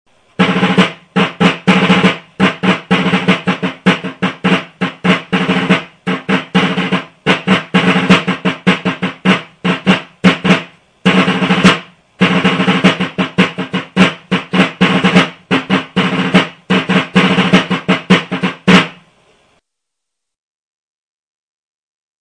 Trommelsignale
(Drummer & Fifer)
Wichtige Trommelsignale und ein paar Rhythmen -